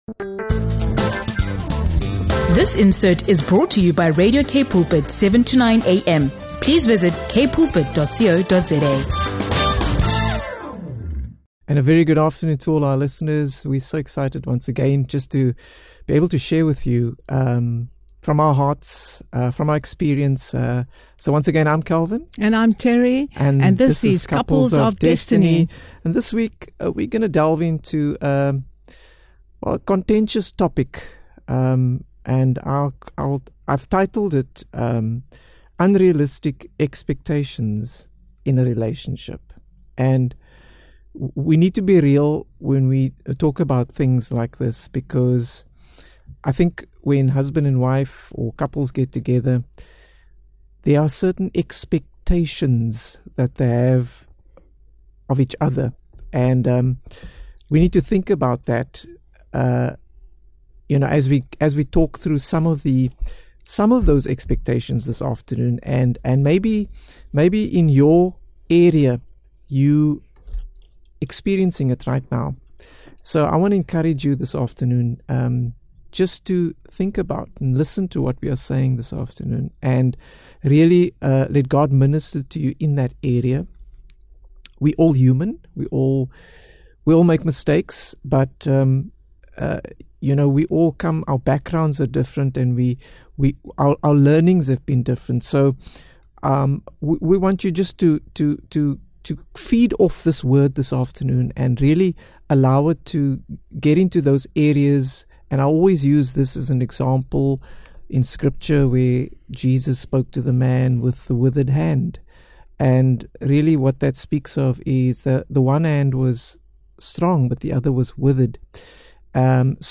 Tune in for a real and practical conversation that will challenge you to release unrealistic expectations, embrace your partner’s uniqueness, and strengthen your marriage in unity and love.